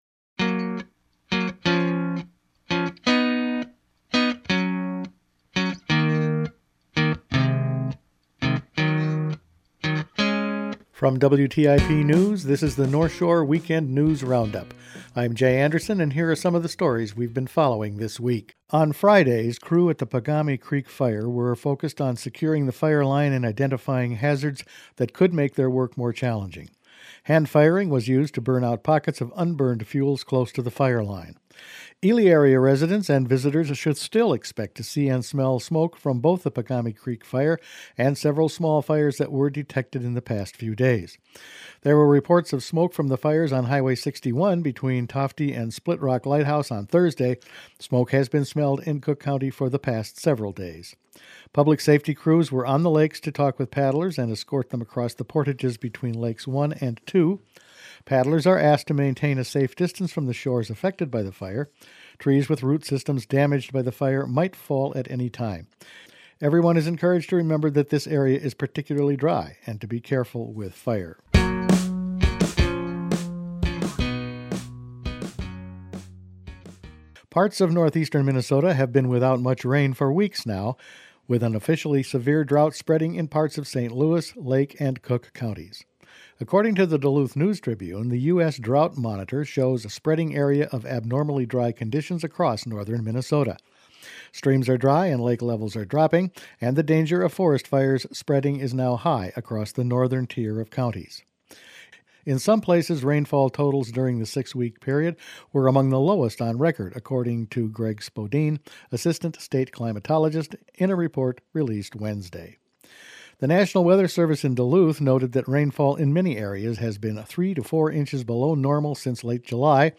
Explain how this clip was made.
Each weekend WTIP news produces a round up of the news stories they’ve been following this week. Fall color predictions, fire and drought as well as a big grant for Lake Superior…all in this week’s news.